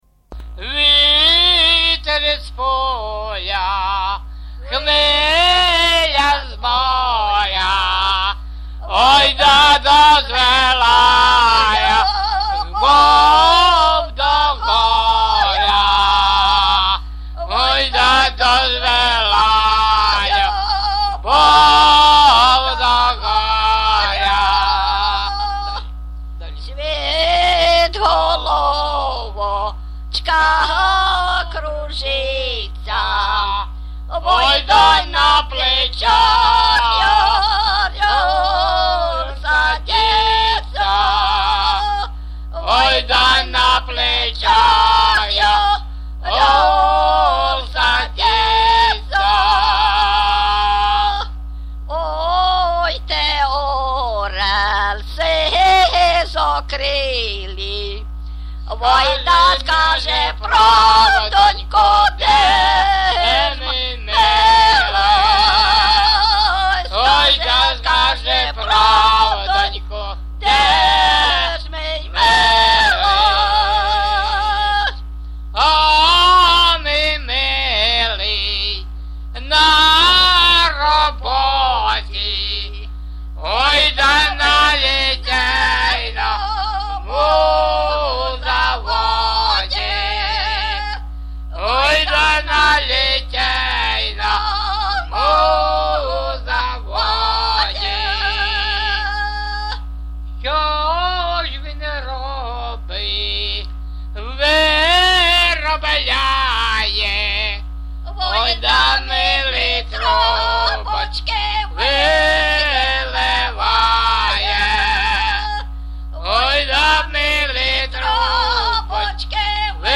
ЖанрПісні з особистого та родинного життя
Місце записус. Нижні Рівні, Чутівський район, Полтавська обл., Україна, Слобожанщина